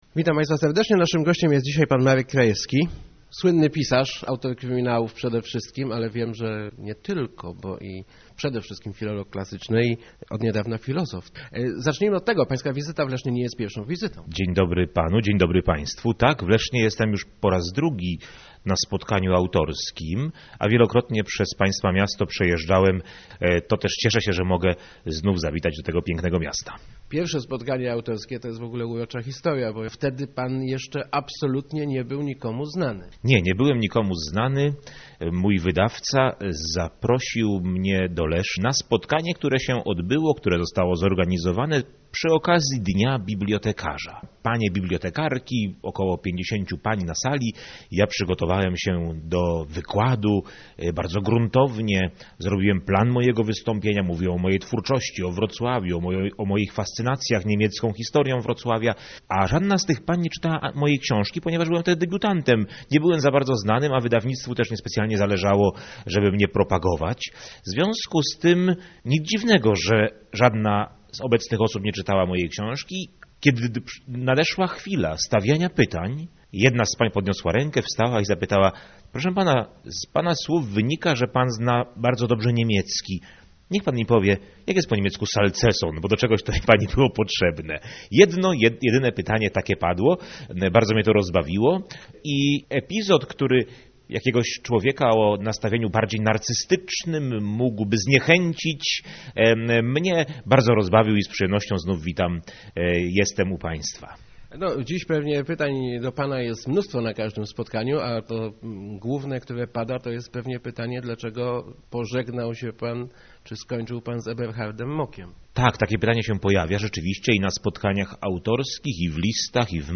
mkrajewski80.jpgEberhardt Mock mi się znudził - przyznaje w Rozmowach Elki Marek Krajewski, słynny autor kryminałów rozgrywających się w przedwojennym Wrocławiu i Lwowie.